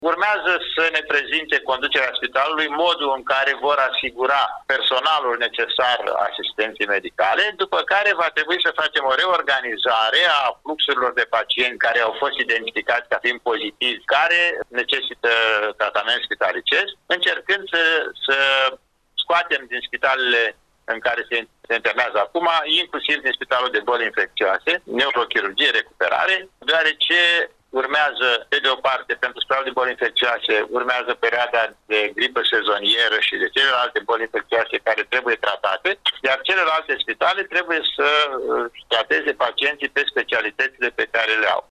Directorul DSP Iaşi, Vasile Cepoi ne-a precizat că unitatea mobilă va funcţiona ca o secţie exterioară a Spitalului de Boli Infecţioase din Iaşi.